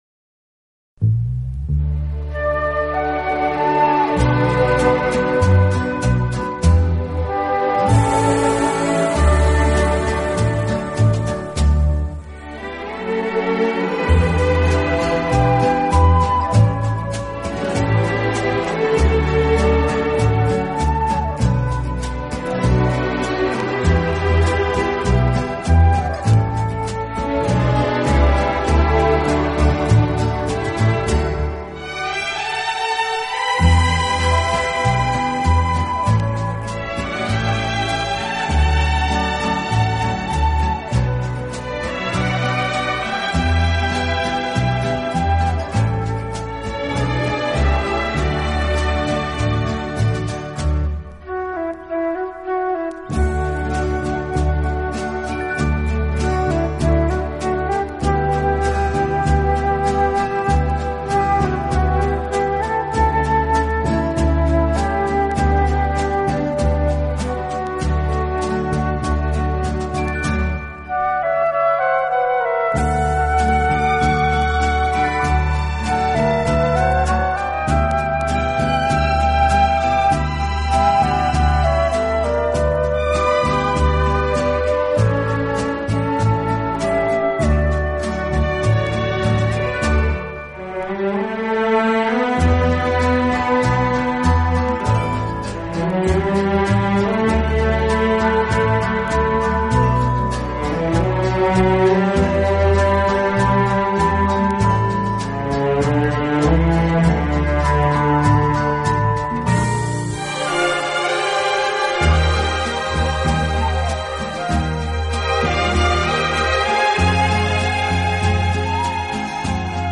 Format: LP RECORD
Genre: 60s Pop, Jazz